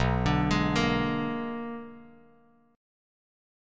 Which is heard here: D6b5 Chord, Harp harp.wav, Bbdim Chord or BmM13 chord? BmM13 chord